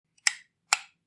Light Switch On